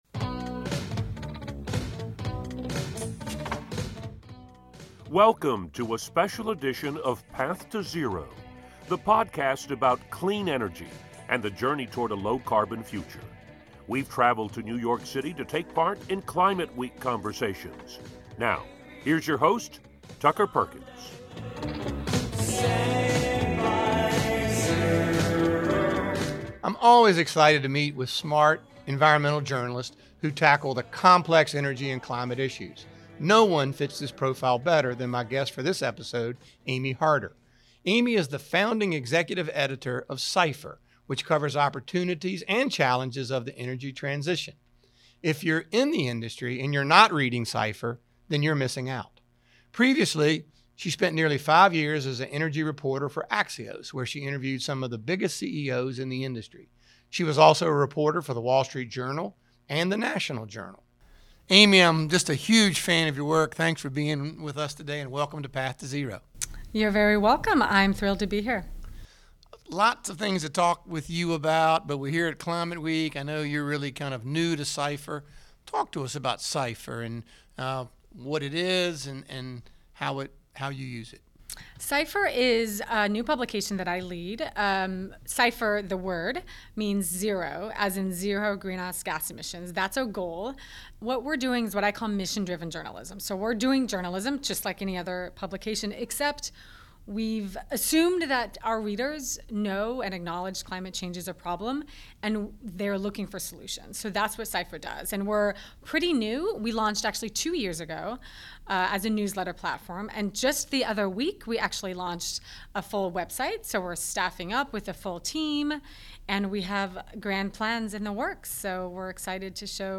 recorded at Climate Week in New York City